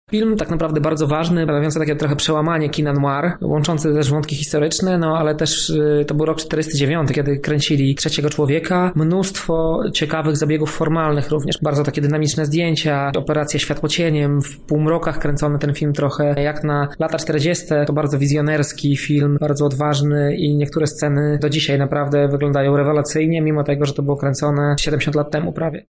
Więcej o filmie mówi